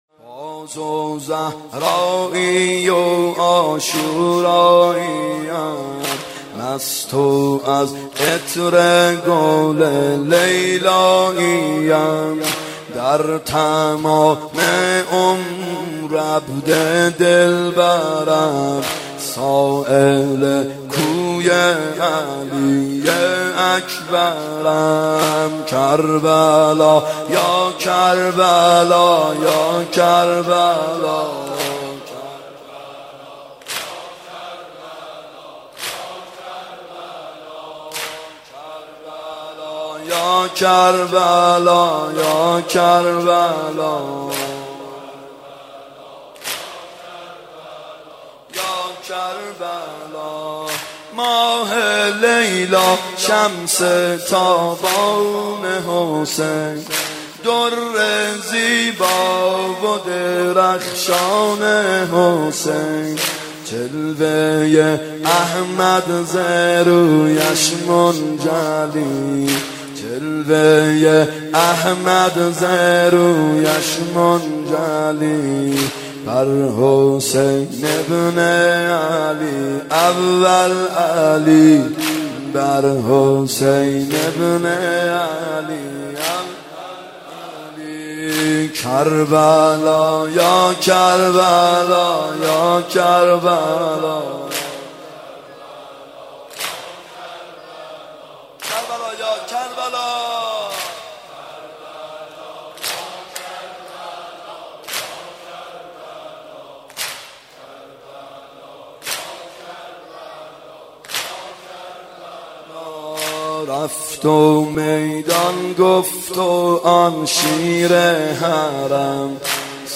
محرم 92 شب هشتم واحد (بازو زهرایی عاشوراییم
محرم 92 ( هیأت یامهدی عج)